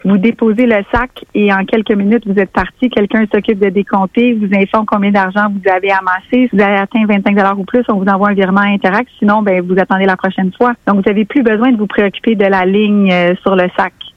En entrevue à Radio-Beauce